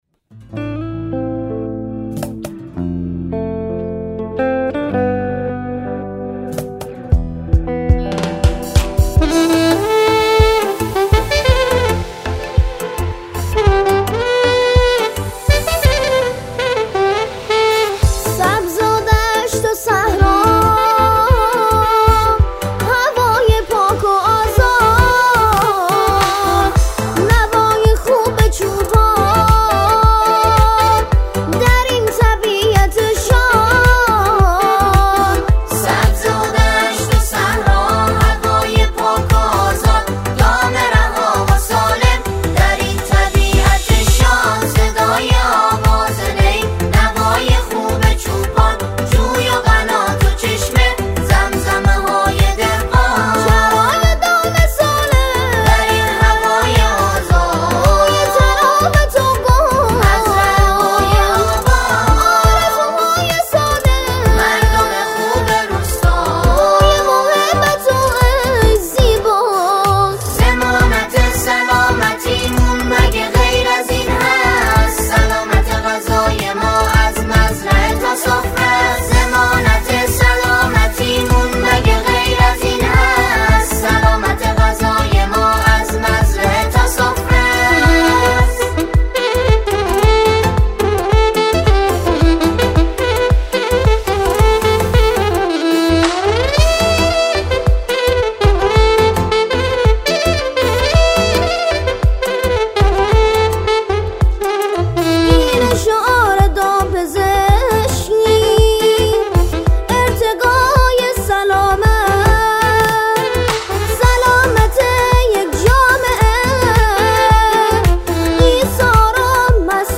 در این قطعه، آن‌ها، شعری را با موضوع روز دامپزشکی می‌خوانند.